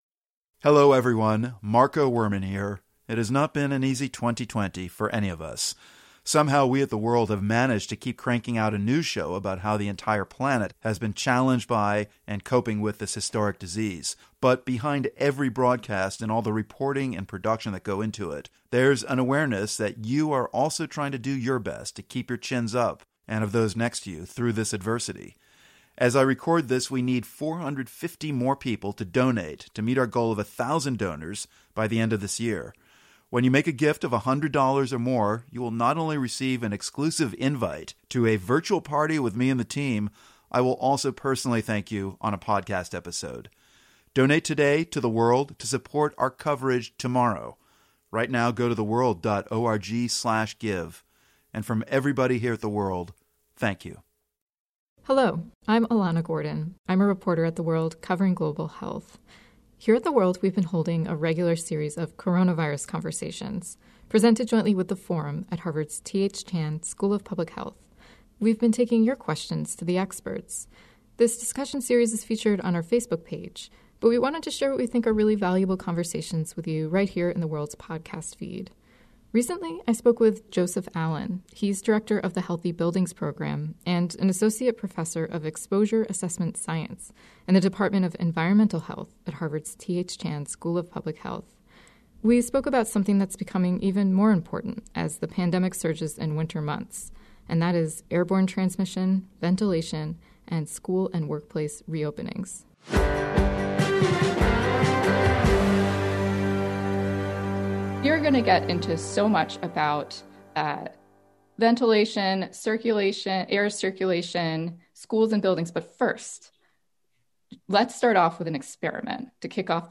What is the latest scientific guidance on airborne COVID-19 transmission? And how might that shape strategies around reopening schools and workplaces? As part of our regular series of conversations about the coronavirus and as a special episode in The World' podcast feed